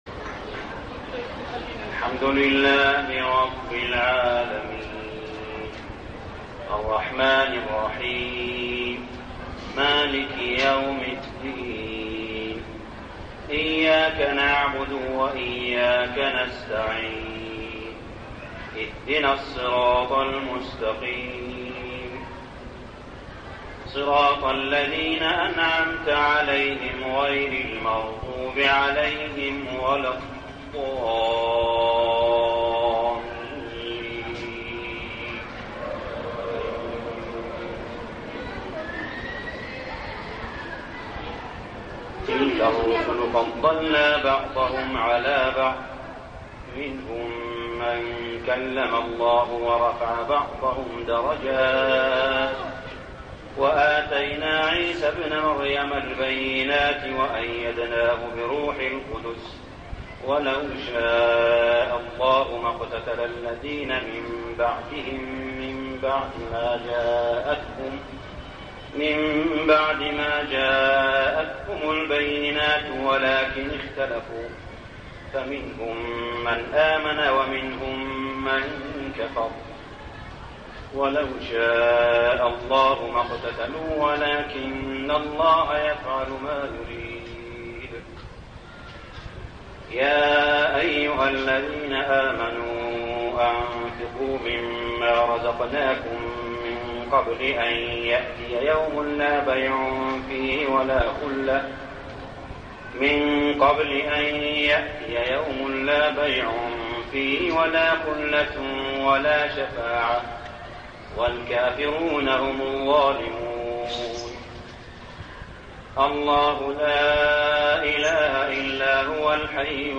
صلاة التراويح ليلة 3-9-1409هـ سورتي البقرة 253-286 و آل عمران 1-14 | Tarawih Prayer Surah Al-Baqarah and Al-Imran > تراويح الحرم المكي عام 1409 🕋 > التراويح - تلاوات الحرمين